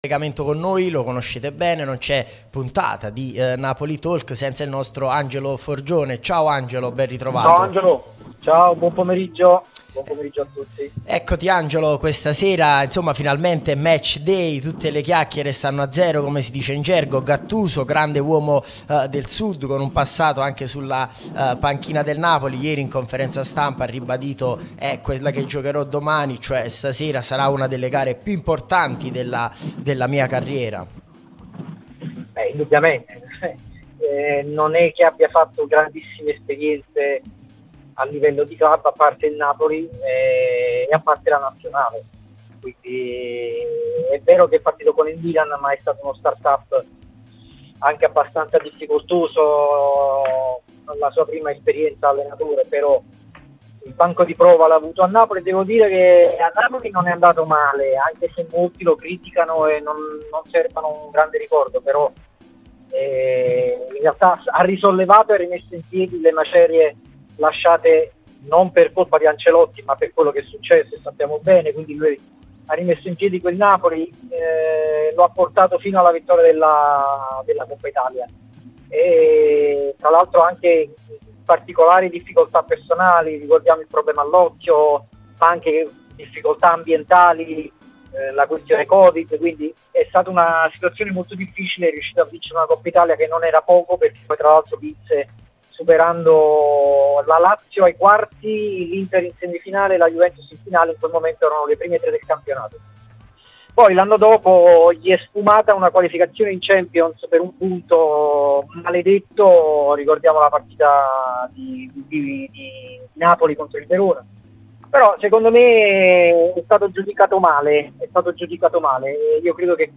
l'unica radio tutta azzurra e sempre live